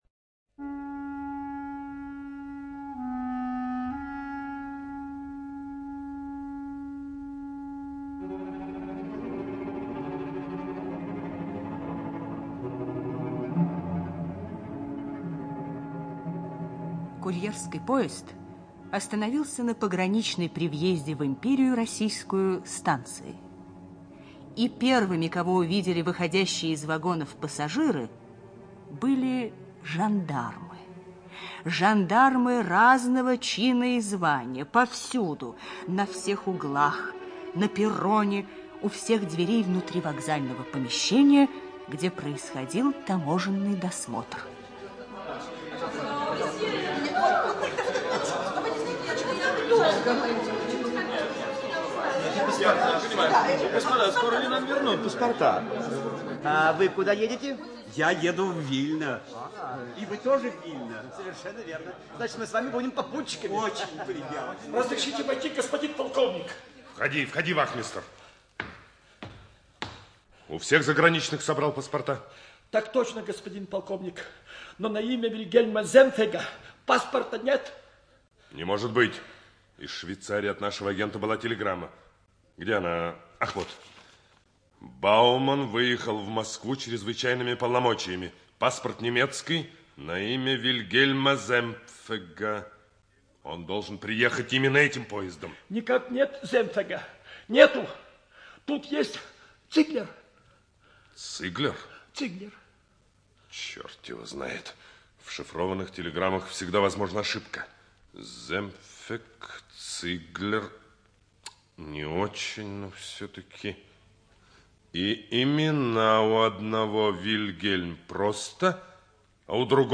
ЖанрДетский радиоспектакль